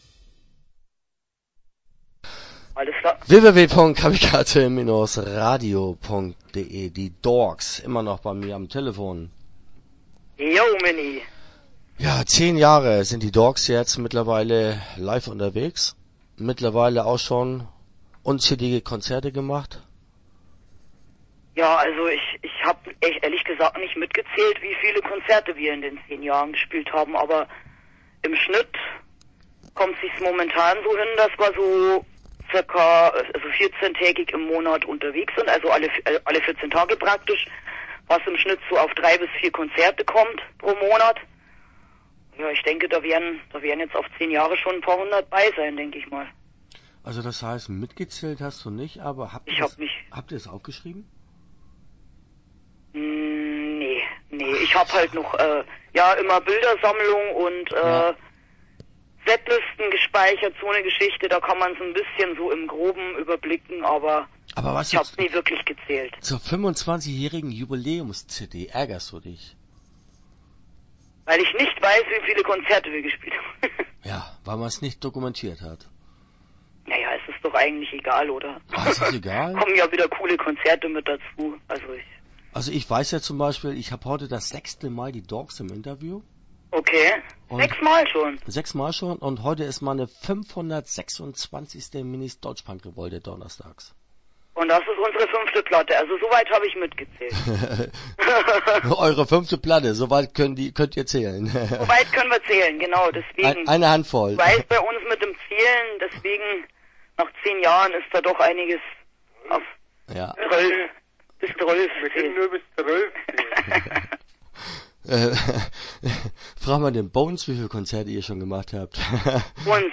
Die Dorks - Interview Teil 1 (11:20)